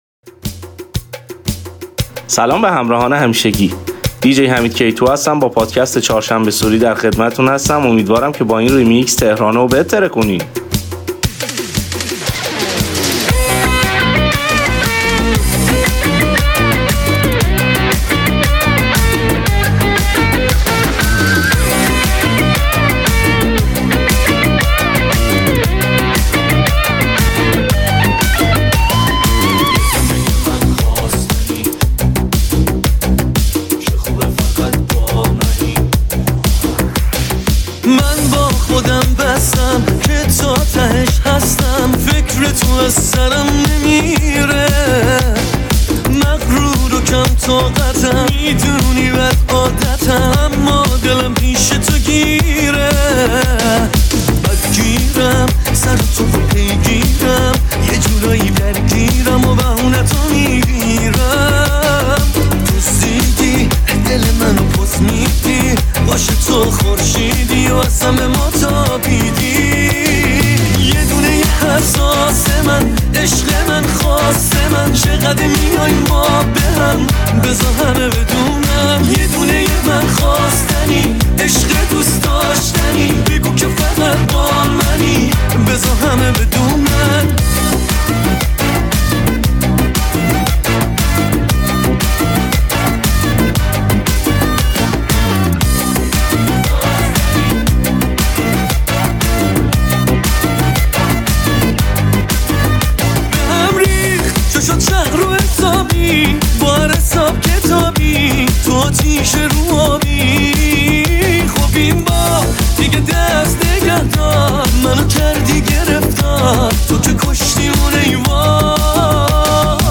طولانی پشت سرهم شاد خفن ایرانی و خارجی